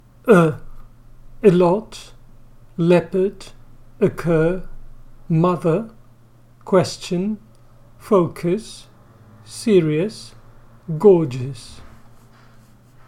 ə
schwa-para-el-cuadro-de-vocales.mp3